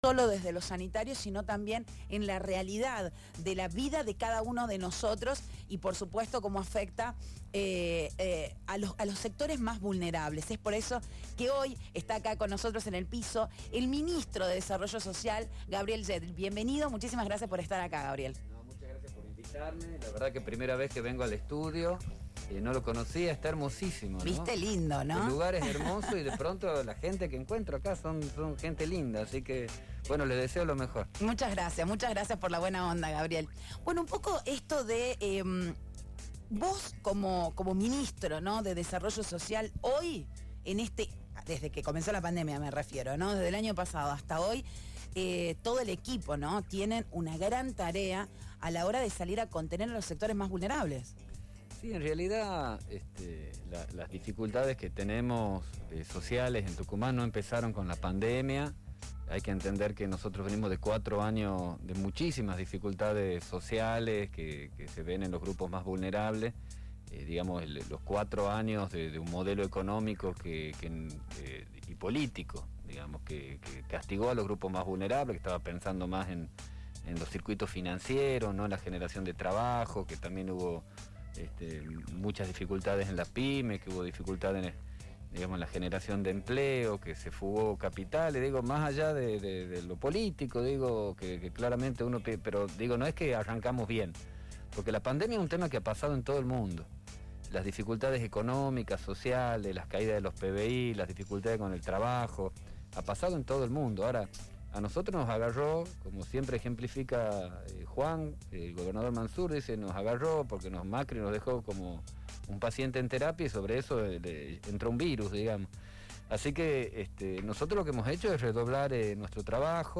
En una entrevista en radio Feel, el ministro de Desarrollo Social, Gabriel Yedlin, hizo una recorrida por los temas sociales más importantes a nivel provincial. También, dialogó sobre cuáles son los desafíos en materia de políticas públicas y la importancia de seguir cuidándonos en un contexto sanitario complejo.